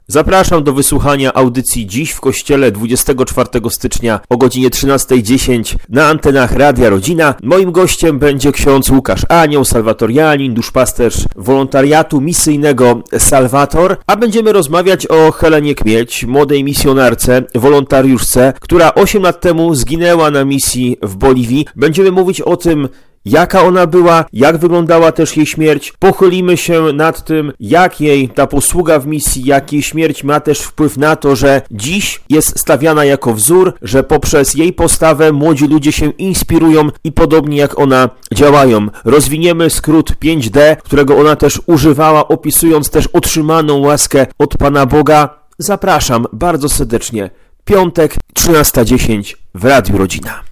Dziewczyna, która stała się wzorem [Zapowiedź]